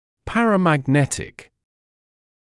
[ˌpærəmæg’netɪk][ˌпэрэмэг’нэтик]парамагнитный